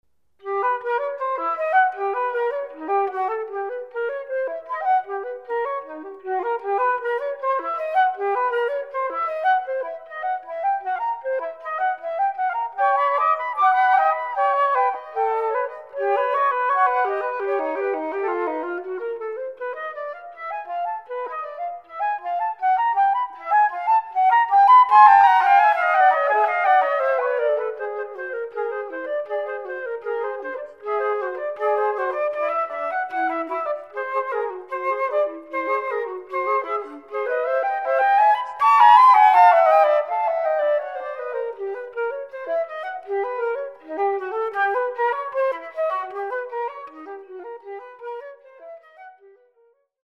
anthology of Canadian flute music
pianist